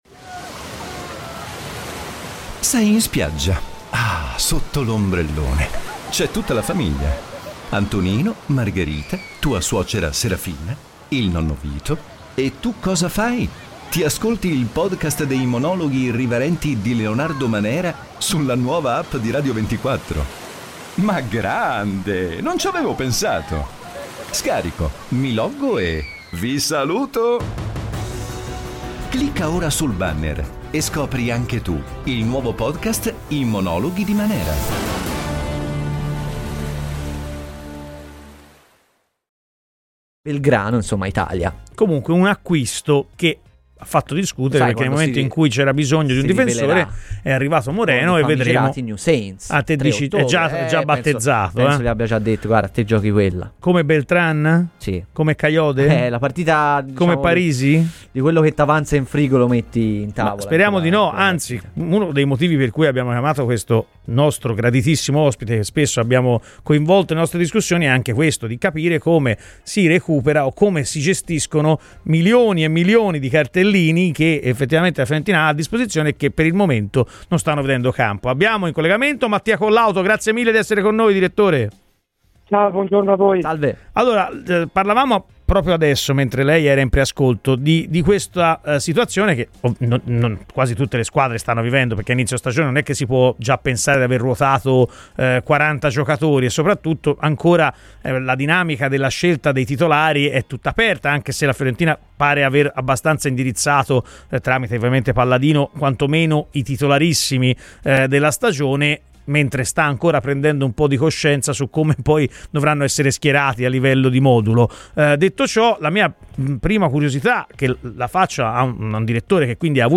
è intervenuto a Radio FirenzeViola durante la trasmissione "Chi Si Compra" per analizzare le tematiche principali di casa Fiorentina.